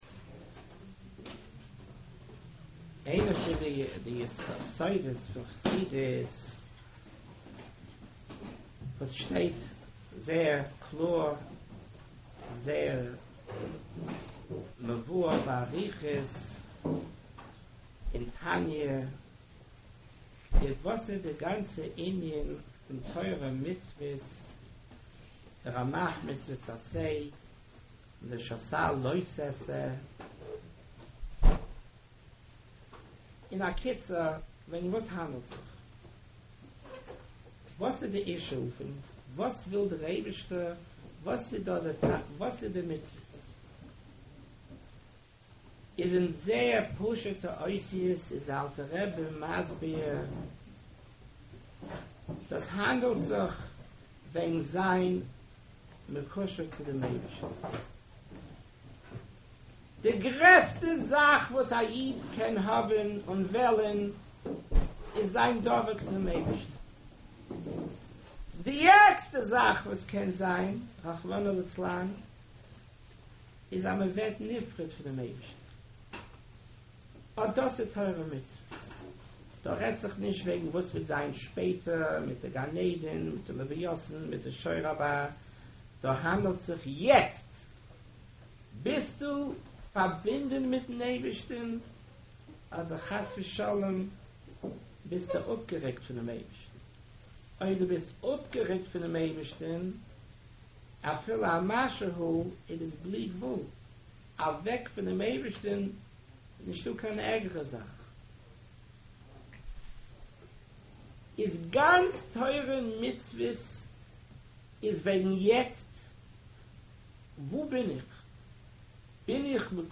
התוועדות יט כסלו חלק ב שנת תשעד